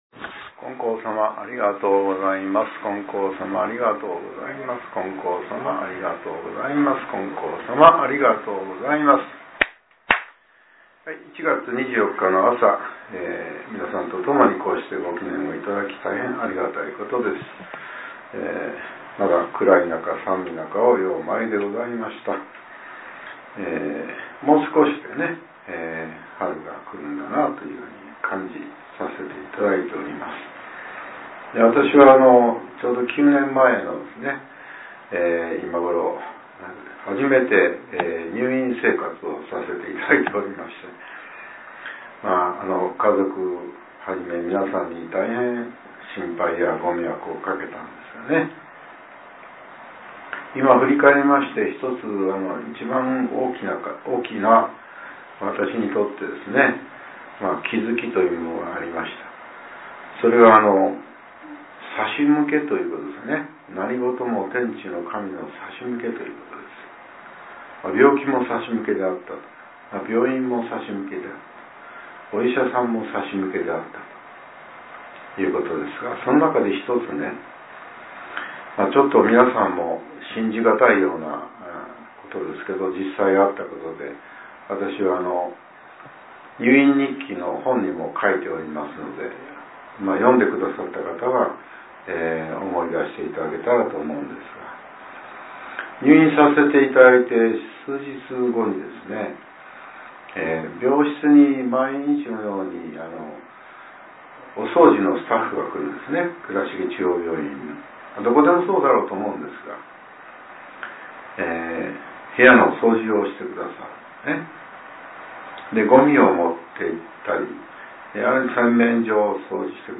令和７年１月２４日（朝）のお話が、音声ブログとして更新されています。